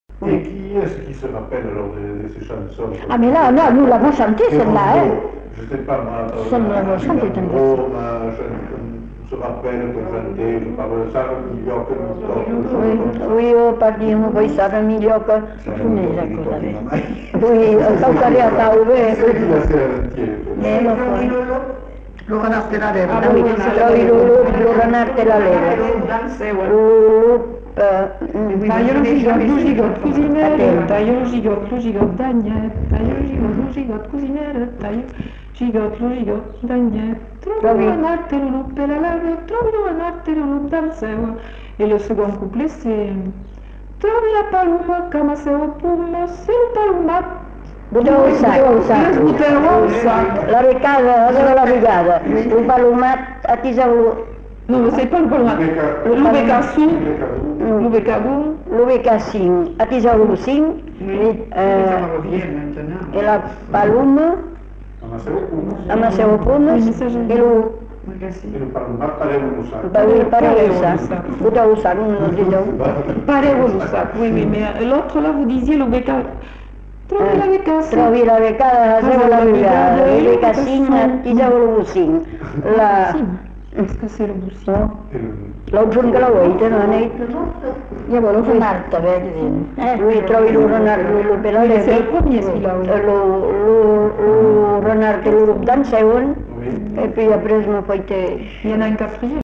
Lieu : Uzeste
Genre : chant
Effectif : 2
Type de voix : voix de femme
Production du son : chanté
Classification : enfantines diverses